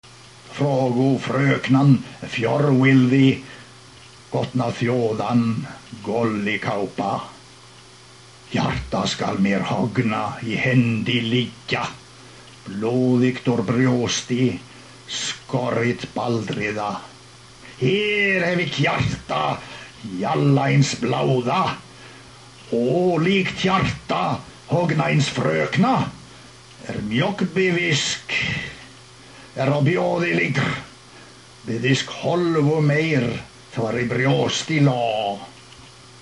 Here’s a recording of a poem being read in a mystery language.